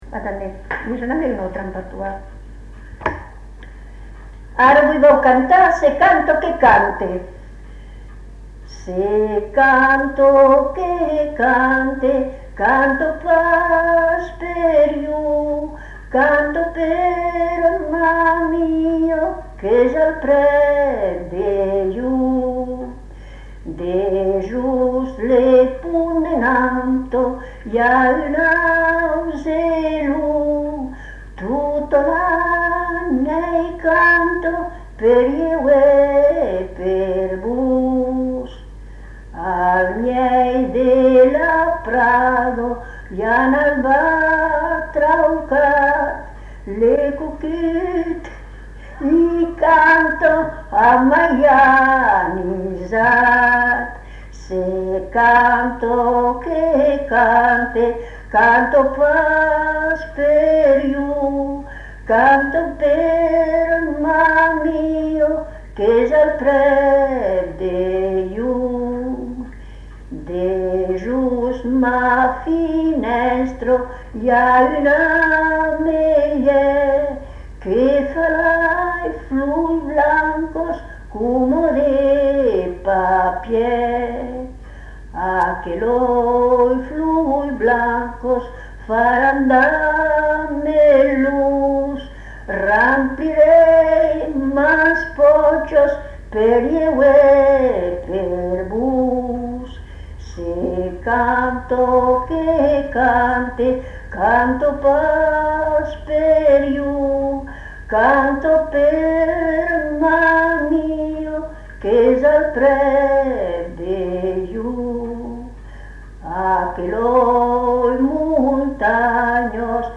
Aire culturelle : Pays toulousain
Genre : chant
Effectif : 1
Type de voix : voix de femme
Production du son : chanté